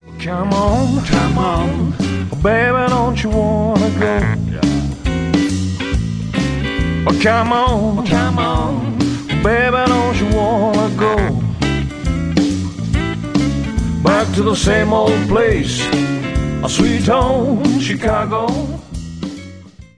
...D'inspiration resolument rock
Un album à taper du pied sans modération...